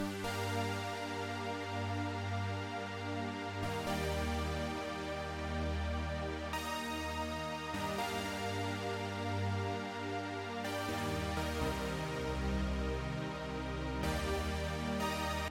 主音合成器
描述：在fl工作室制作的合成器循环
Tag: 70 bpm RnB Loops Synth Loops 4.26 MB wav Key : Unknown